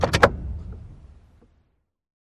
Cars
shutdown.wav